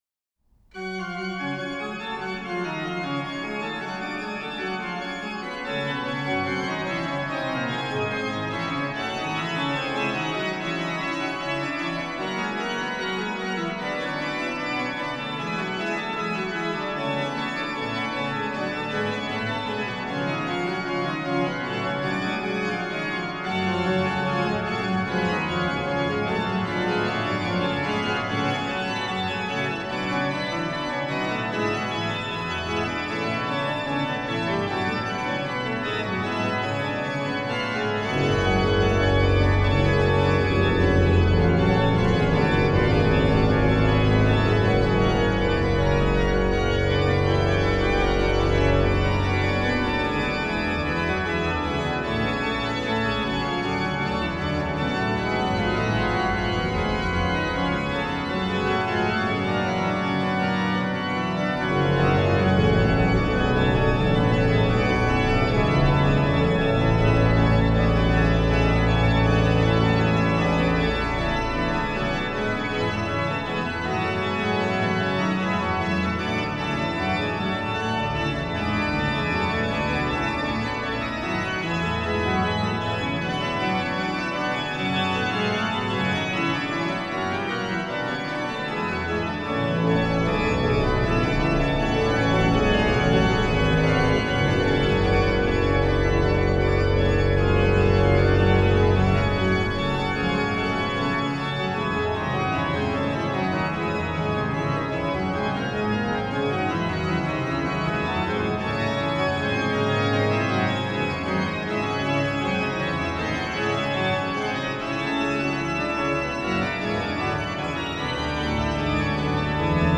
Subtitle   in organo pleno; il canto fermo nel pedale
Ped: Unt32, Pr16, Oct8, Oct4, Pos16